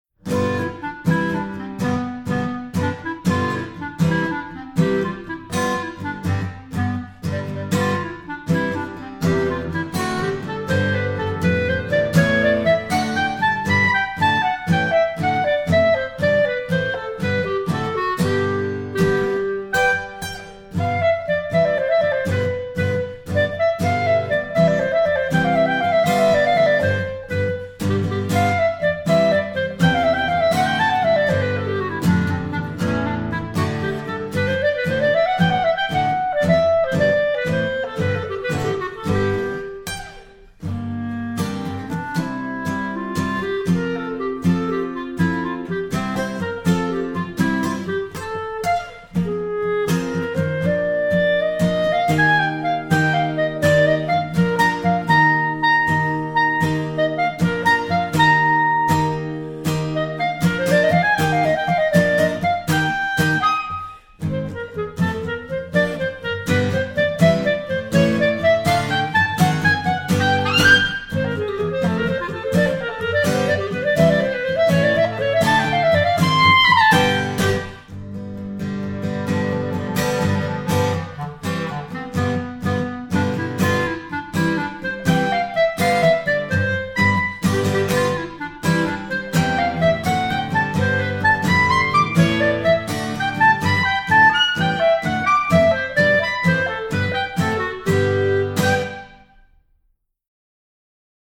clarinet
guitar